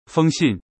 ピンインfēng xìnnán yáng jiāng jūn
*2 音声は音読さんを利用